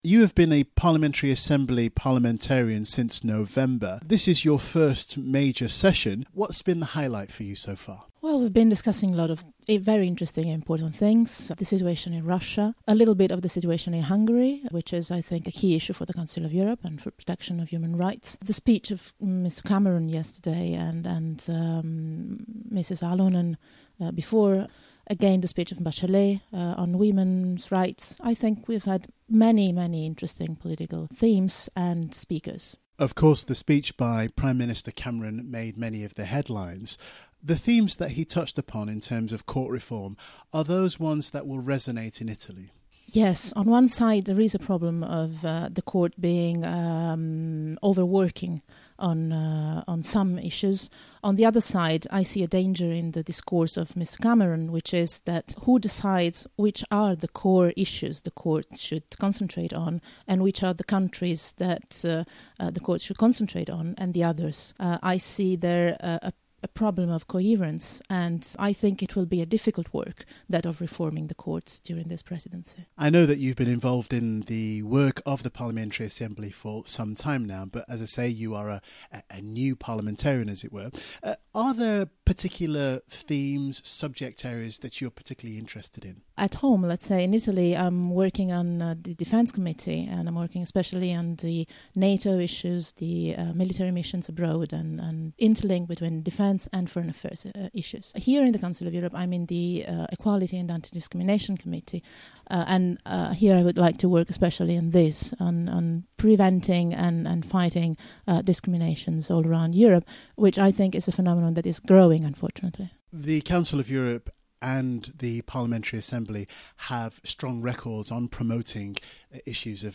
Interview with Federica Mogherini - Part 1